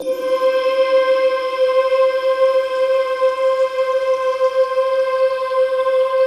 Index of /90_sSampleCDs/Optical Media International - Sonic Images Library/SI1_RainstickChr/SI1_RainstickMix